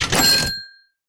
kaching.mp3